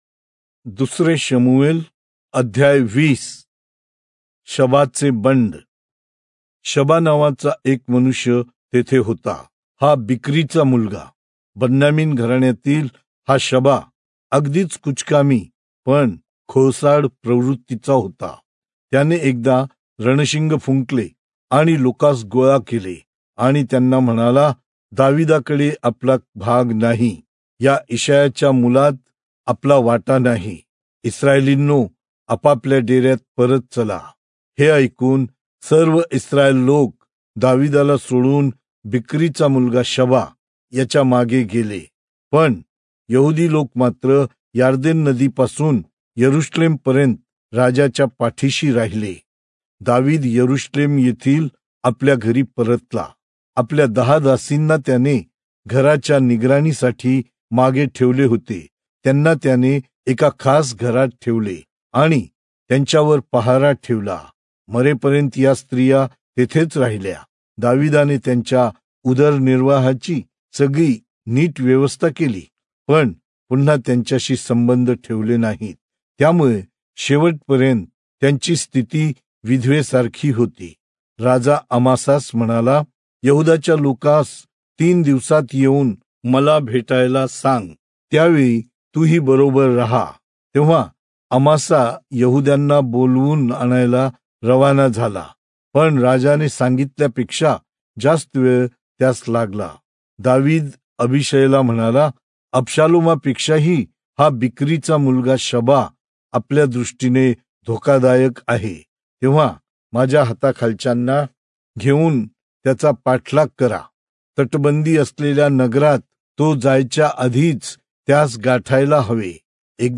Marathi Audio Bible - 2-Samuel 18 in Irvmr bible version